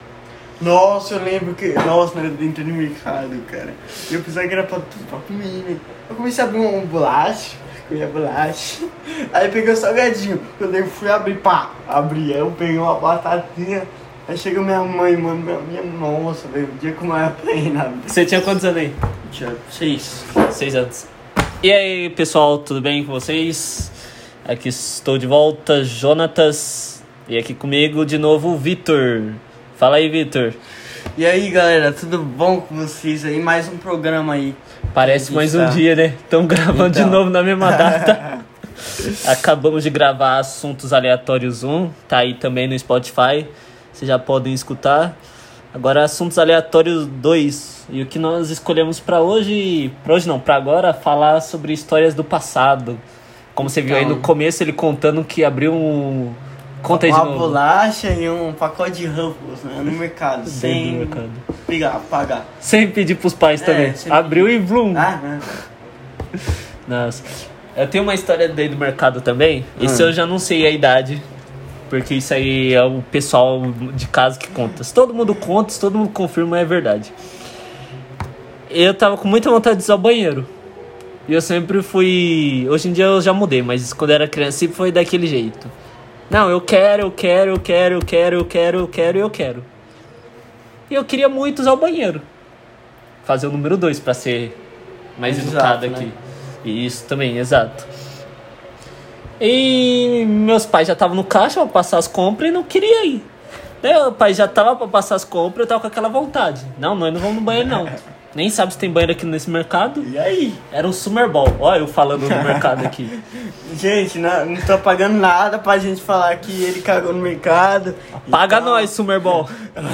AQUI SÓ PUBLICO PODCAST SEM EDIÇÃO! CONVERSAMOS SOBRE HISTÓRIAS QUE MARCARAM NOSSA INFÂNCIA!